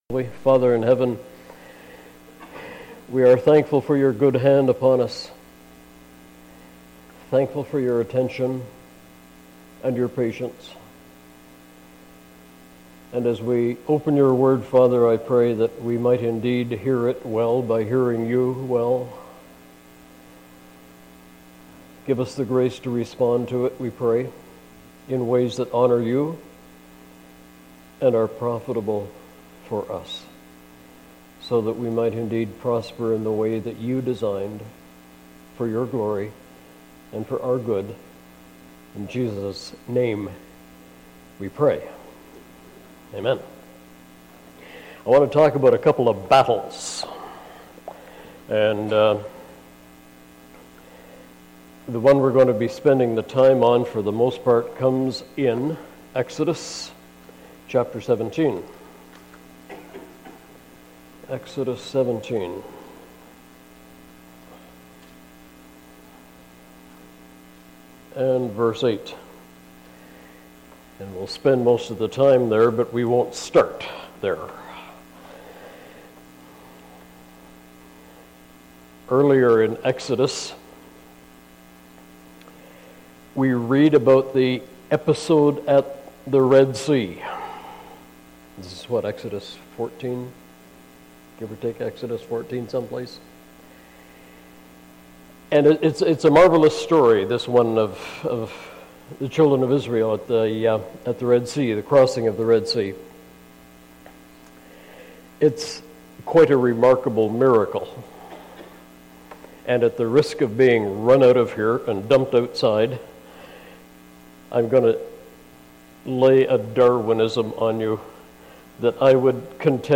Bible Text: Exodus 17:8-16, Deut. 25:17,19 | Preacher